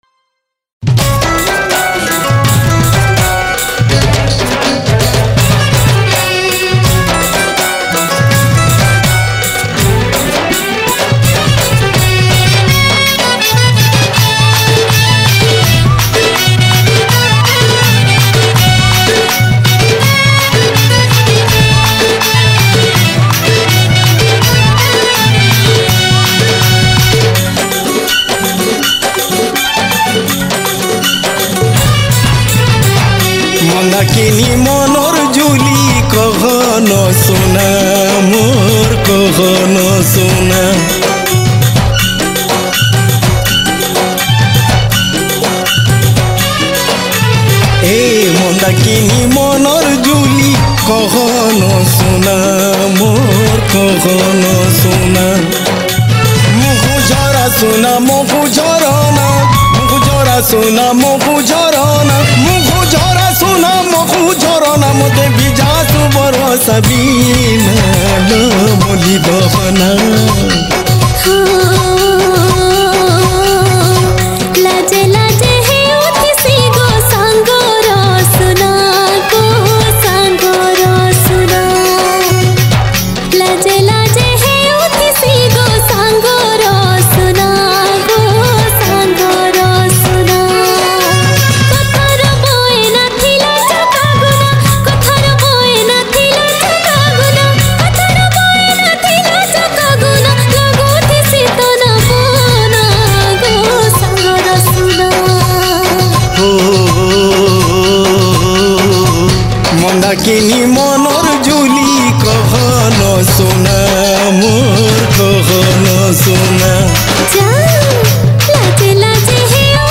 Sambalpuri Song
Sambalpuri Songs